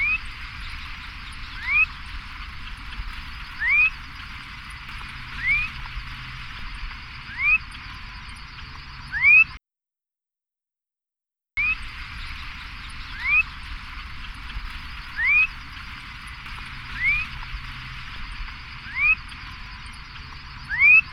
Heteroxolmis dominicana - Viudita blanca grande
Tyrannidae
Viudita blanca grande.wav